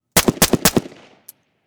MultiShot.wav